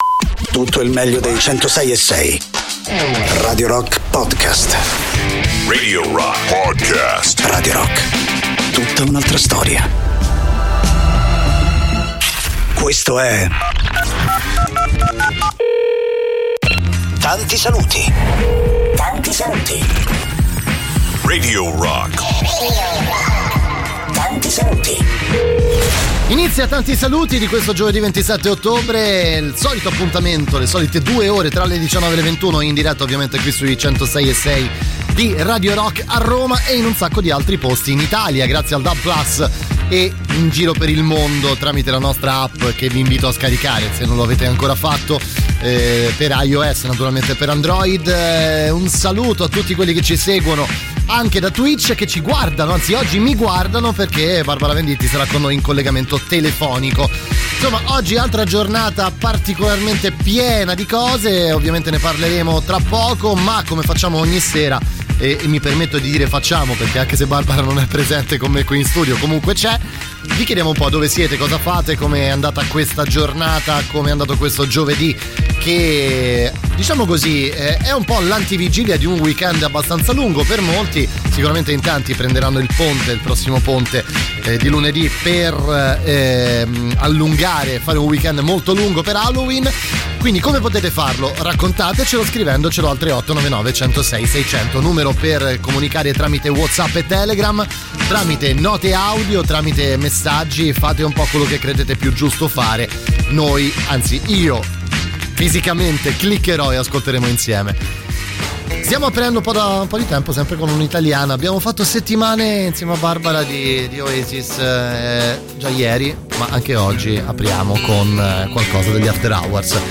in diretta dal lunedì al venerdì, dalle 19 alle 21, con “Tanti Saluti” sui 106.6 di Radio Rock